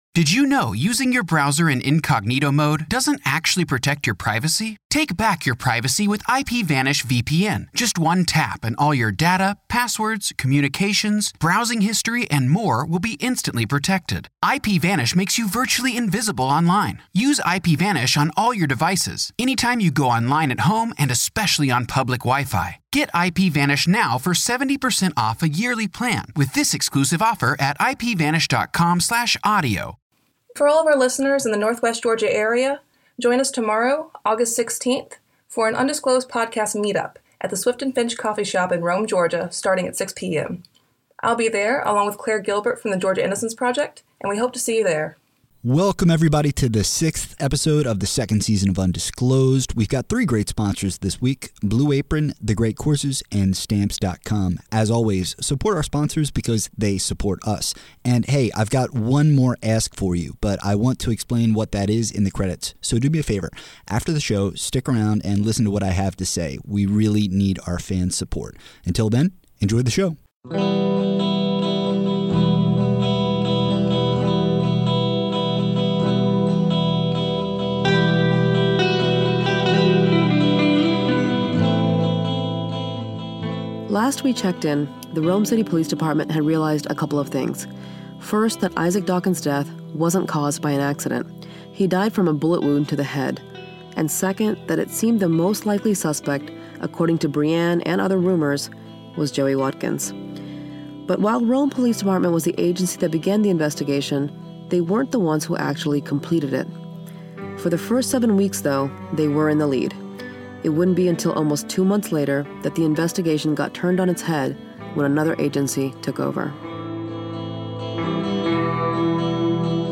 Episode scoring music